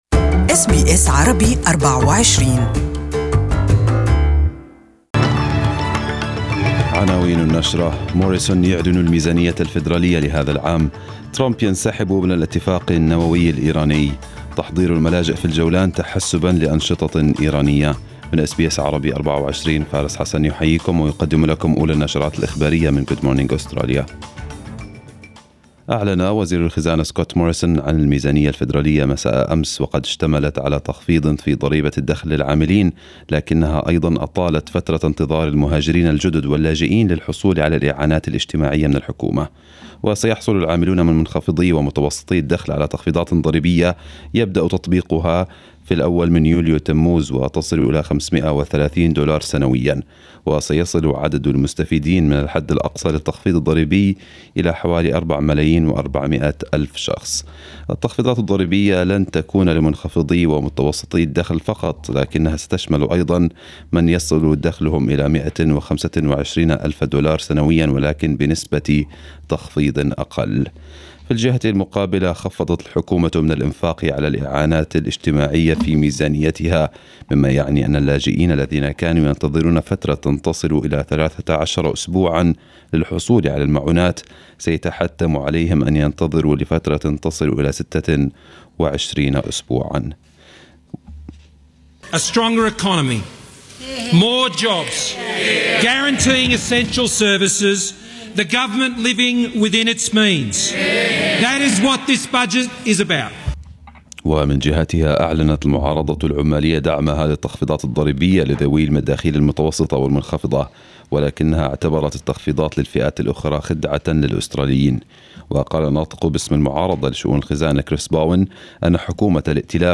Arabic News Bulletin 09/05/2018